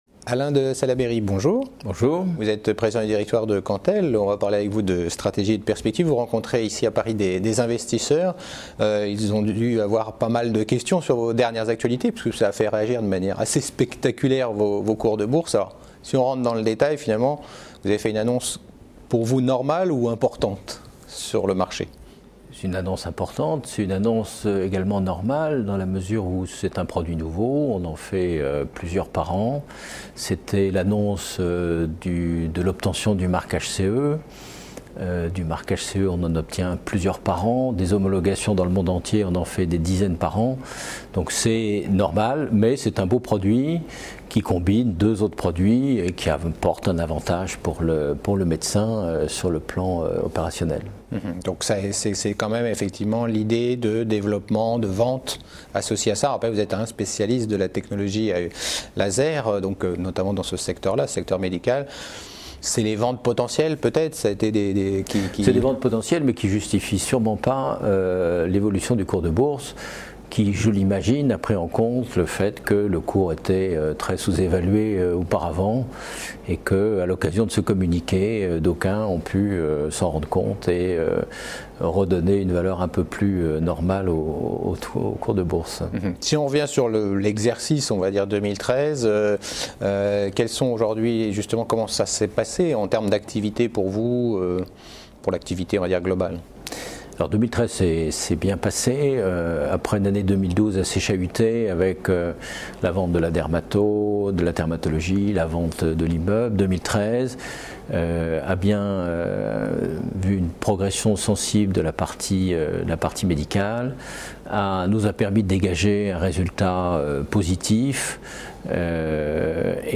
Rencontre à l'European Small Cap Event